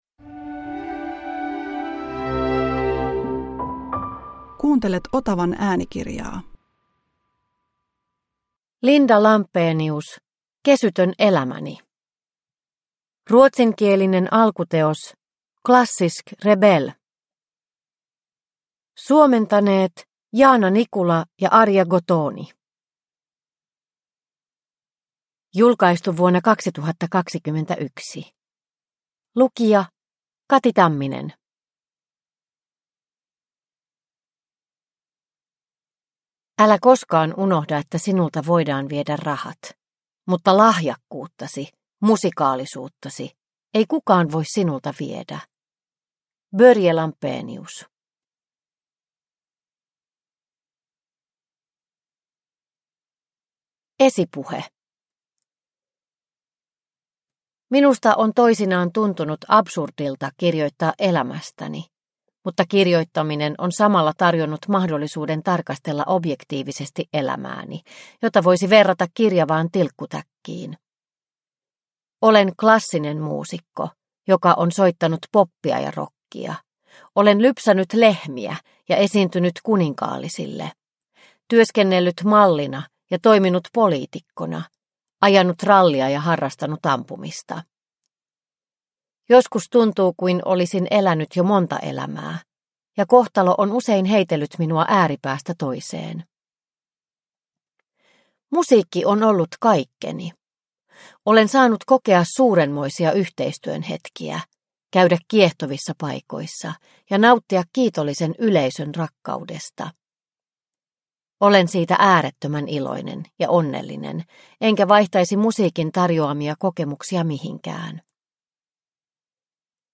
Kesytön elämäni – Ljudbok – Laddas ner